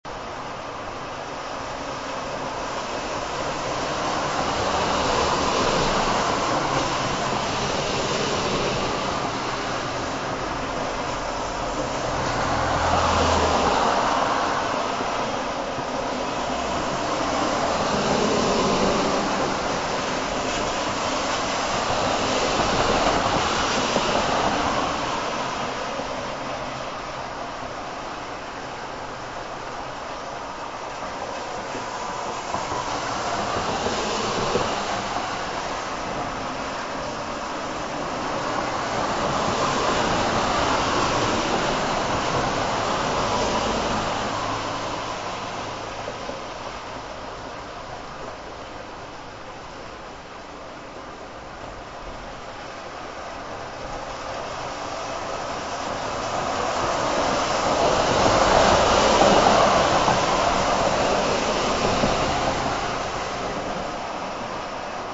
Road Trip in the Rain
Note that the samples are mp3 (lower quality than the CD) to keep download times short.
As the rain falls, the stream of travelers continues, charging through torrents of water, spraying clouds of mist in all directions. In the ebb and flow of rain and travelers, the sound of engines purring and rubber cutting through water is almost hypnotic.
off-white-noise-road-trip-in-rain-60s.mp3